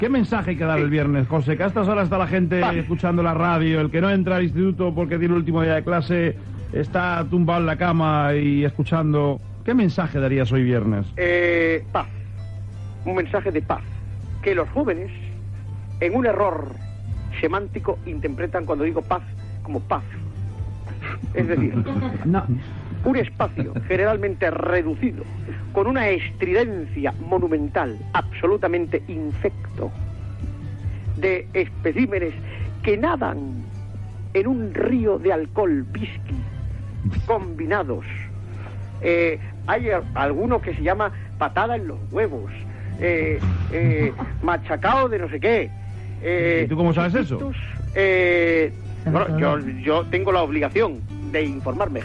Fragment d'una entrevista
Entreteniment